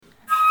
Flötensolo
flute-21st-july-137903.mp3